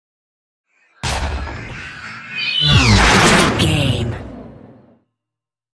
Double hit with whoosh sci fi
Sound Effects
Atonal
futuristic
tension
woosh to hit